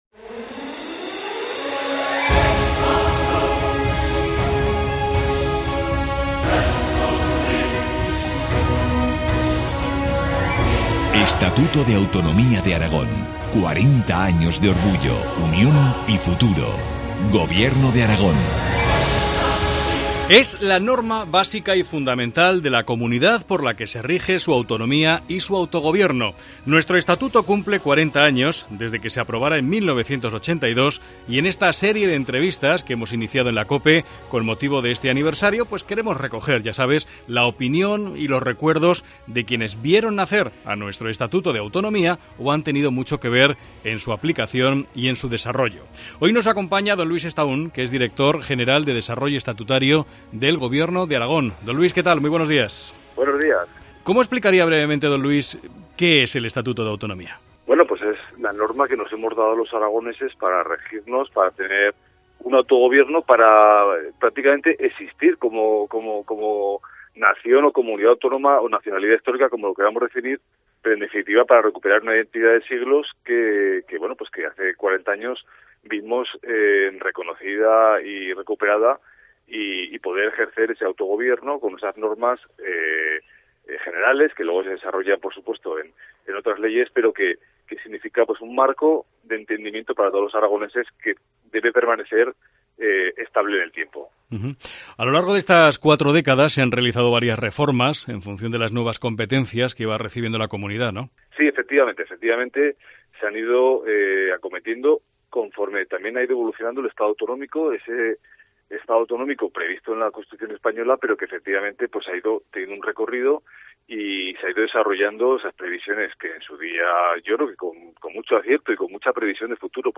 Entrevista a Luis Estaún, director general de Desarrollo Estatutario del Gobierno de Aragón.